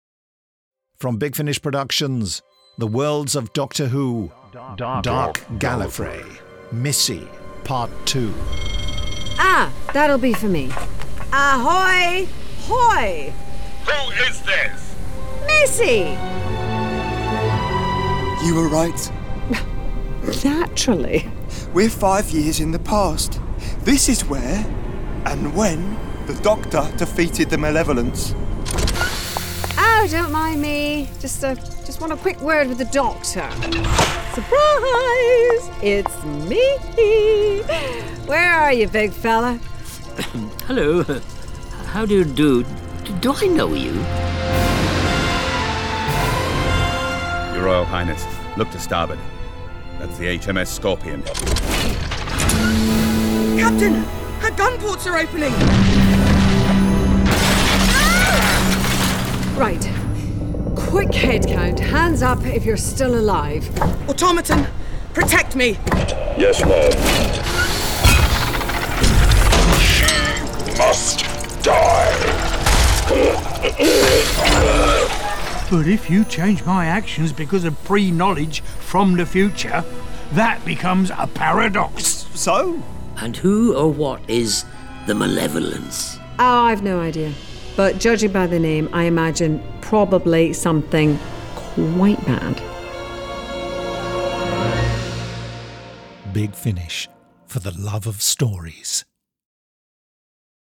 Starring Michelle Gomez Sylvester McCoy